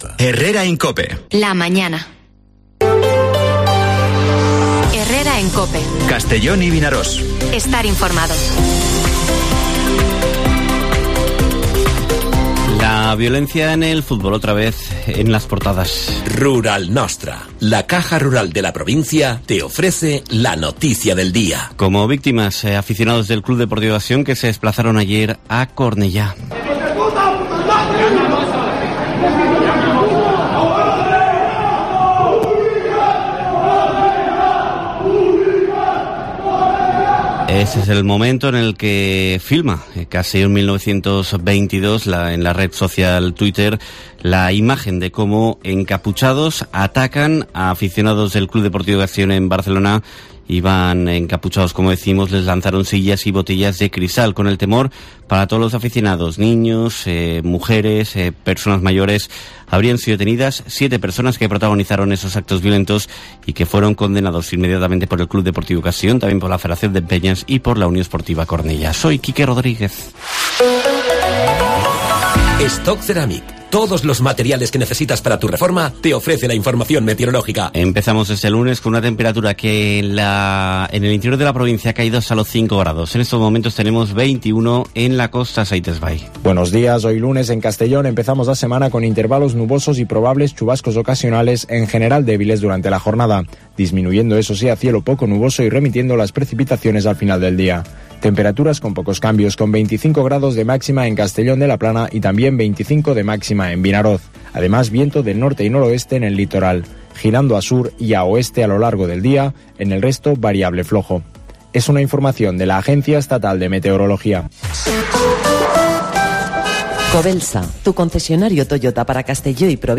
Las noticias con las que Castellón inicia el lunes en Herrera en COPE: Agresión a seguidores del Castellón en Barcelona; Feria azulejera de Cersaie en Italia; Benicàssim despide las Fiestas de Santo Tomás de Villanueva; Deportes...